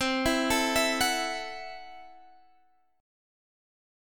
C6b5 chord